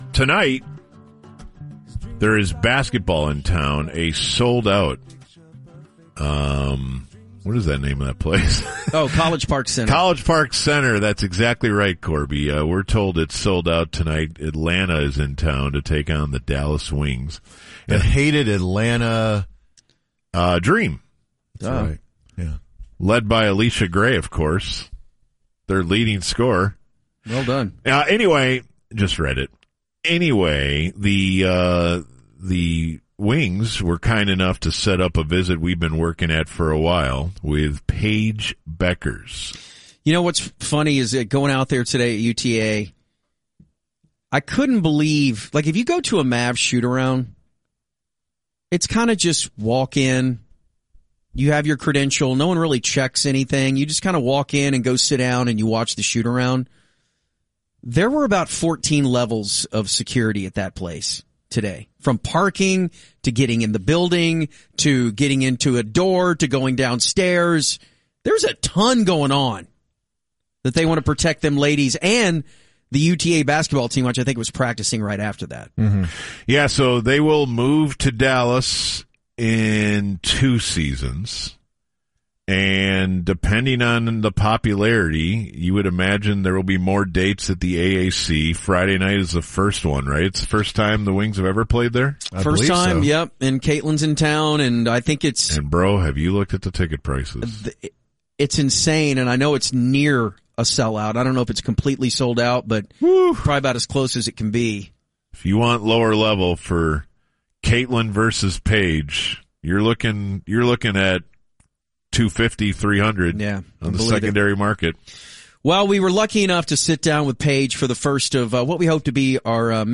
THL talks to Paige Buckets